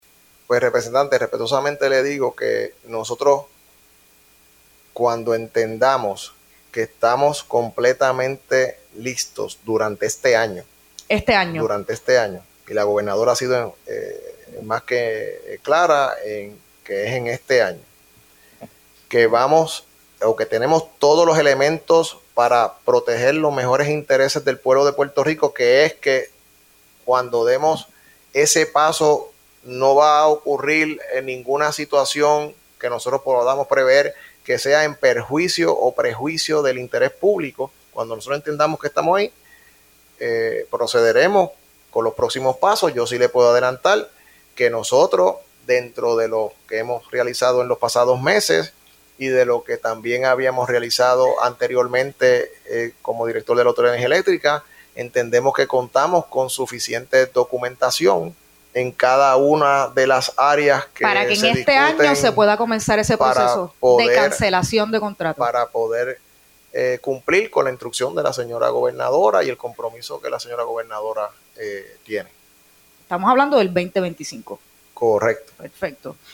El ingeniero Josué Colón participó de una vista pública en la Comisión del Gobierno de la Cámara de Representantes, en relación a la Resolución de la Cámara 42